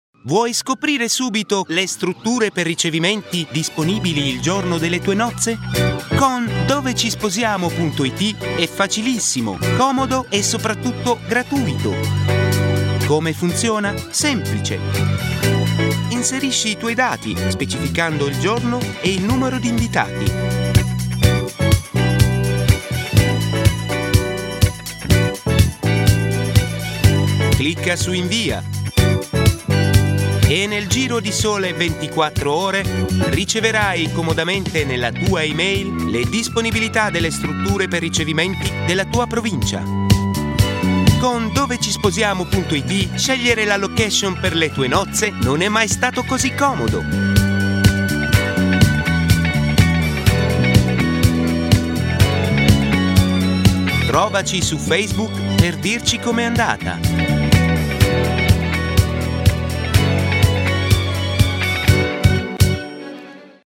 Native speaker Male 20-30 lat
High-range, melodious, cheerful voice.
Spot reklamowy